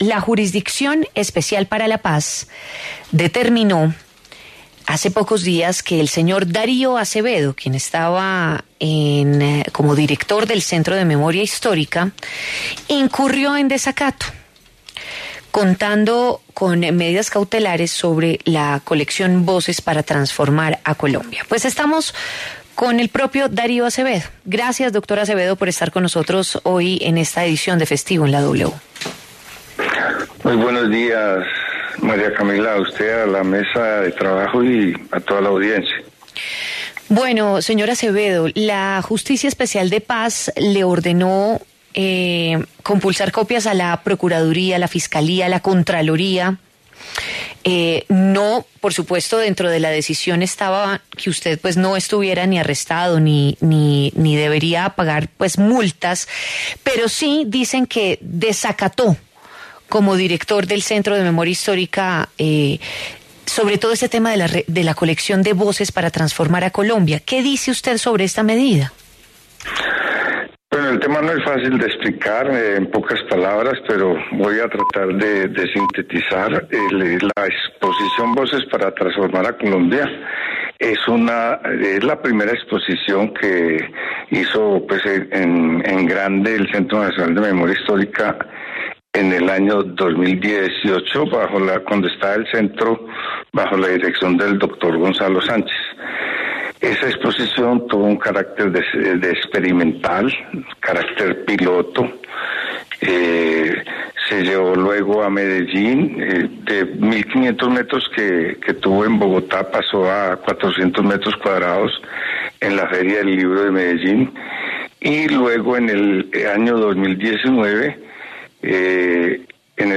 Darío Acevedo, exdirector de Centro de Memoria Histórica, habló en La W sobre la investigación que piden en su contra por posibles ilegalidades.